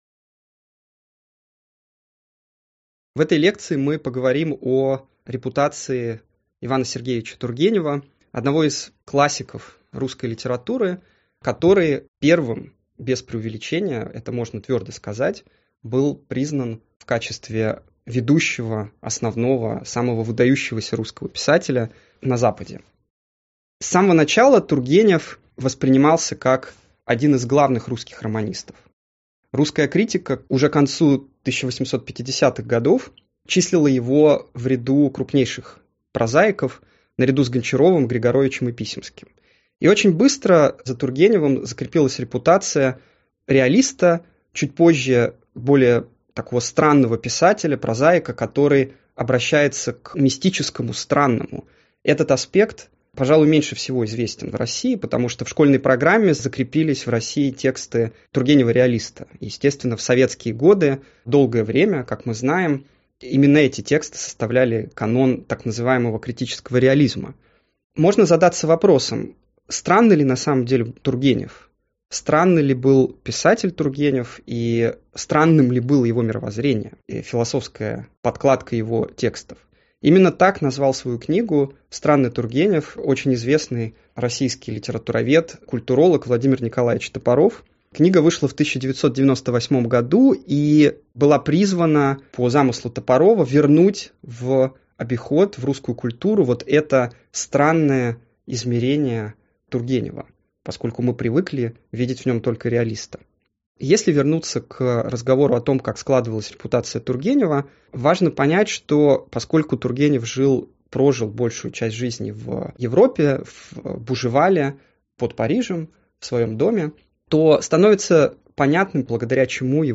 Аудиокнига Странный Тургенев? Загадка для литературоведов.